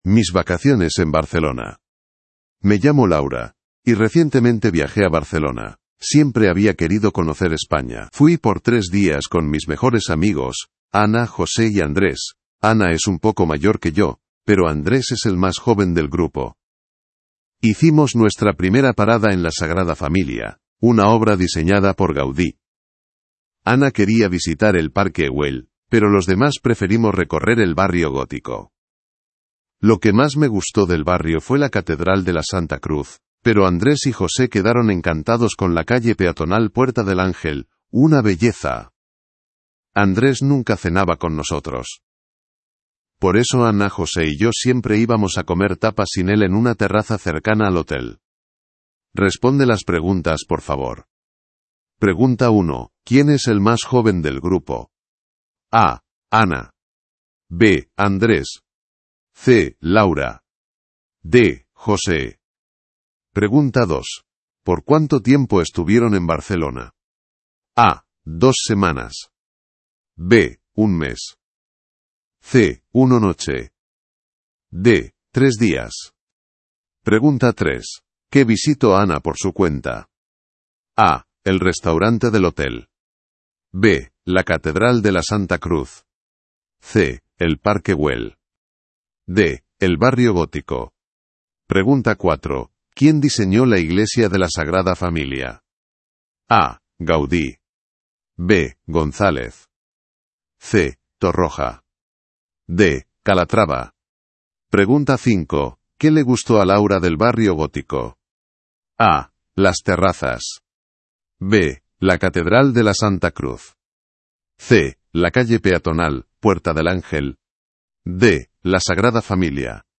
Espagne